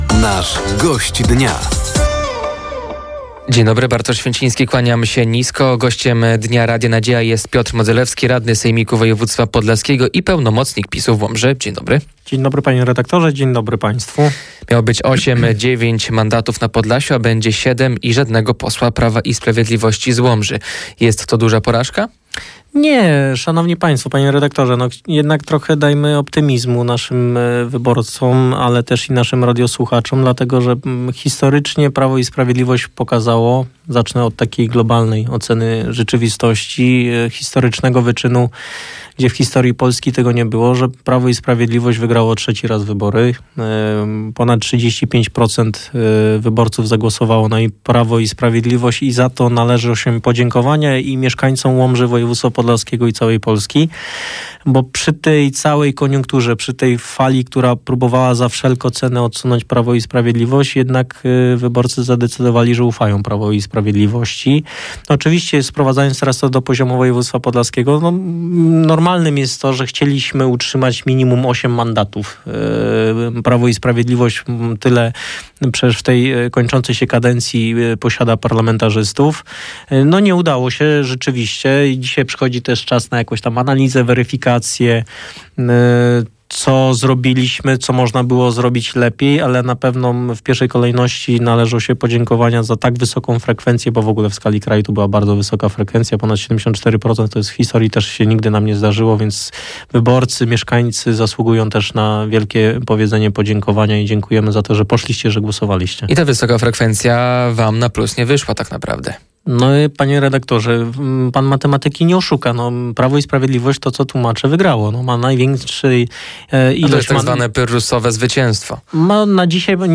Gościem Dnia Radia Nadzieja był radny sejmiku województwa podlaskiego i pełnomocnik Prawa i Sprawiedliwości w Łomży, Piotr Modzelewski. Tematem rozmowy były wyniki wyborów.